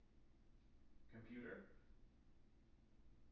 wake-word
tng-computer-219.wav